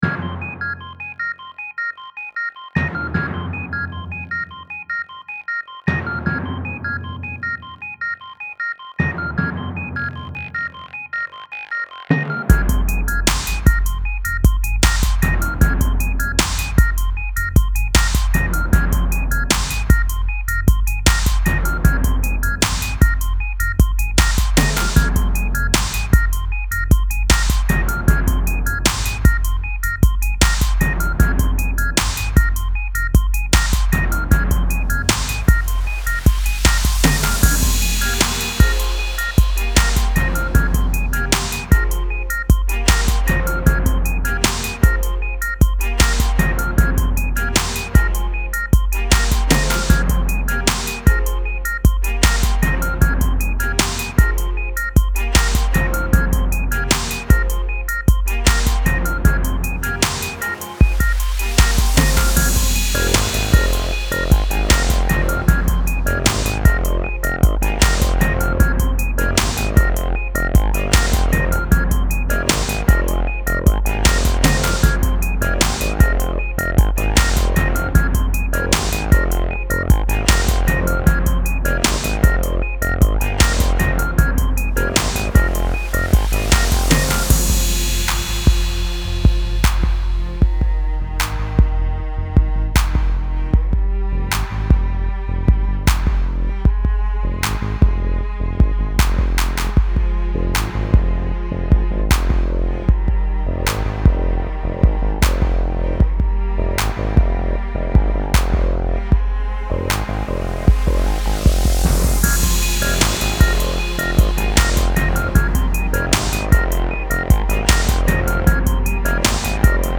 Style Style EDM/Electronic, Hip-Hop
Mood Mood Intense
Featured Featured Bass, Cello, Drums +2 more
BPM BPM 77